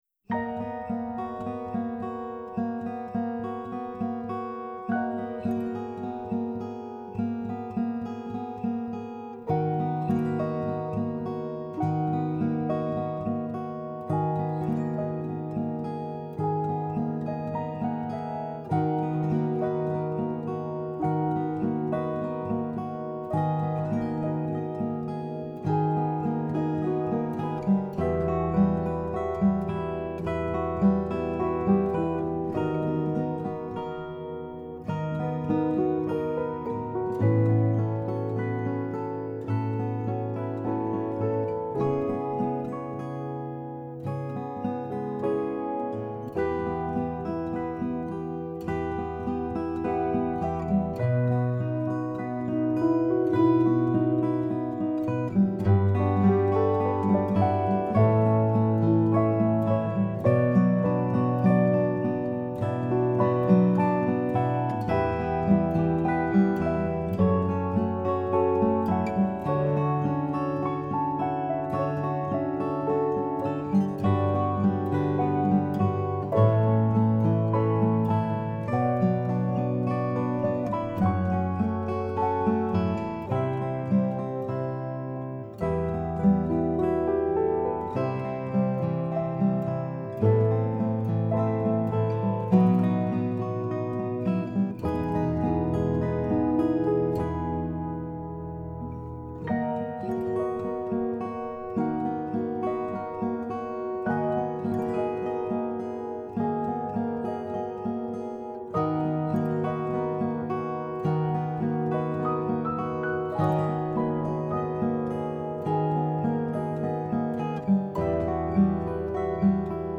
My post title is a lyric line from my song “Take Me Away.” Recently, my piano guy created a lovely addition for this song.
take-me-away-piano-guitar-6-18-19.mp3